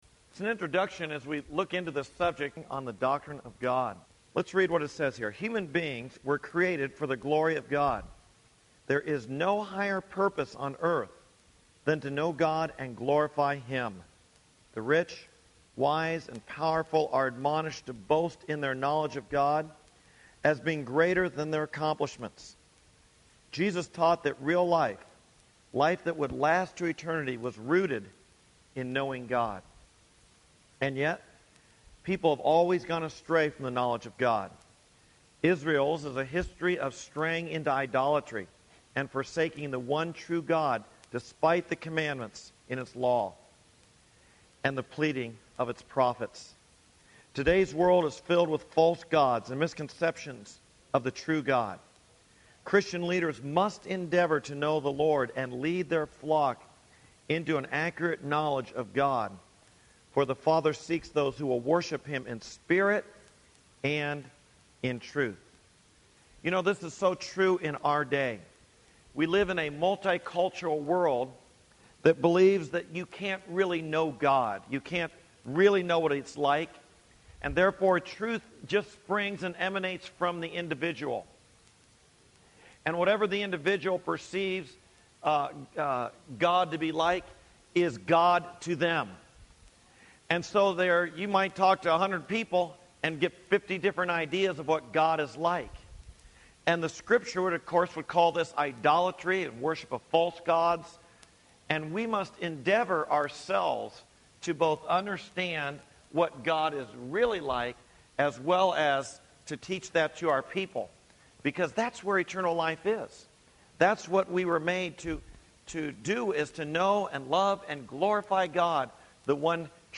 Our audio sermon podcast is available on most podcasting services including Spotify, Apple Podcasts, Stitcher, Google Podcasts and more!